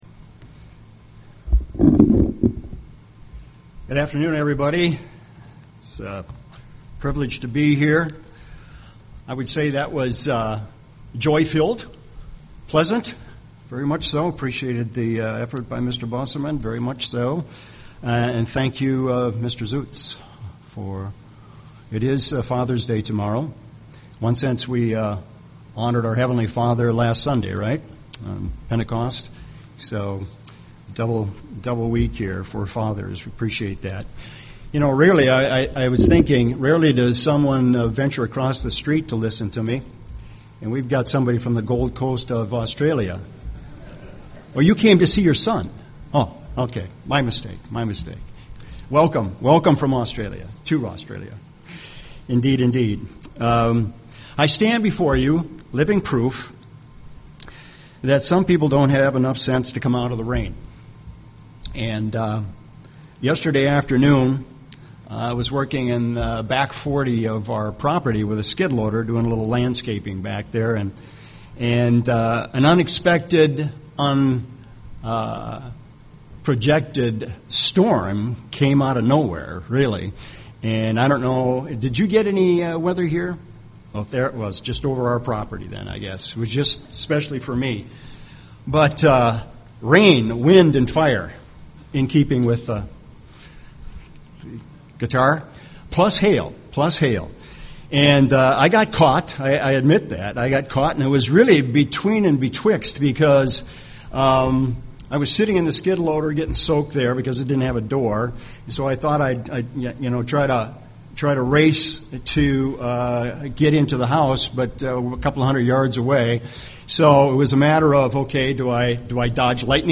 UCG Sermon Samaritan Studying the bible?